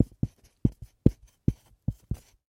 Звуки маркеров
Пишем маркером на специальной доске